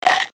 MG_sfx_vine_game_bananas.ogg